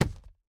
Minecraft Version Minecraft Version 25w18a Latest Release | Latest Snapshot 25w18a / assets / minecraft / sounds / block / chiseled_bookshelf / insert4.ogg Compare With Compare With Latest Release | Latest Snapshot